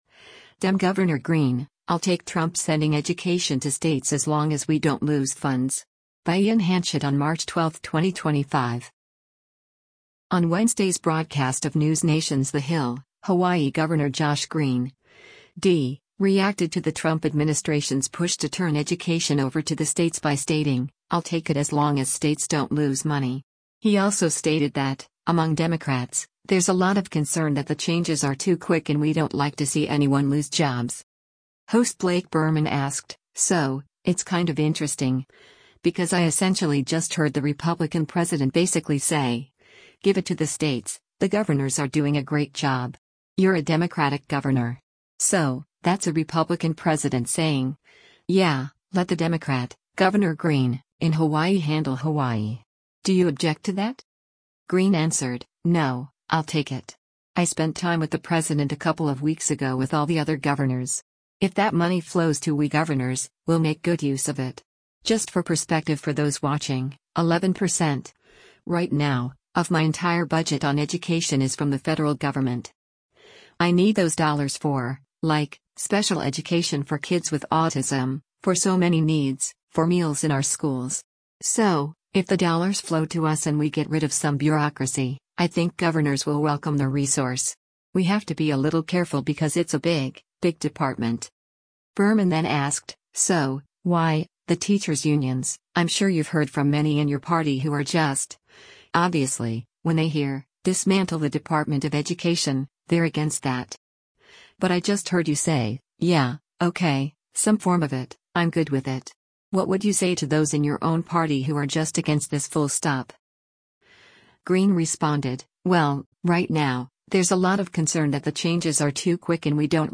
On Wednesday’s broadcast of NewsNation’s “The Hill,” Hawaii Gov. Josh Green (D) reacted to the Trump administration’s push to turn education over to the states by stating, “I’ll take it” as long as states don’t lose money.